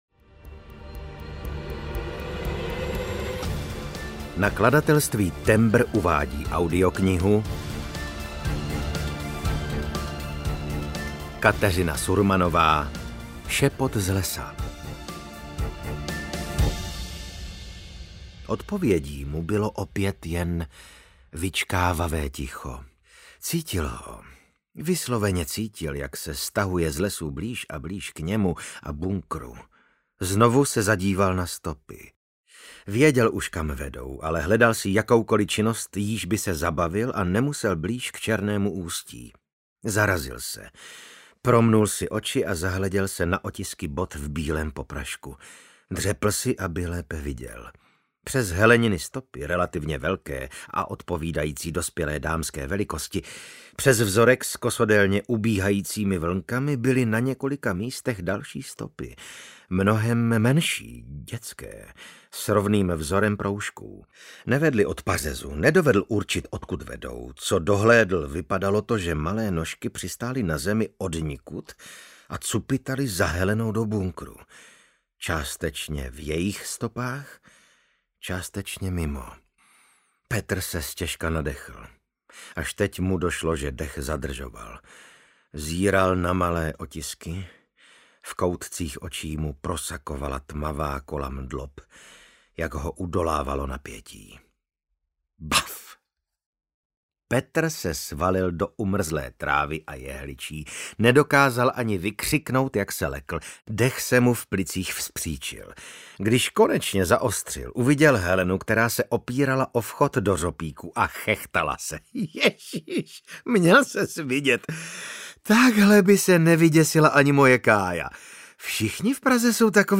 Šepot z lesa audiokniha
Ukázka z knihy
• InterpretVasil Fridrich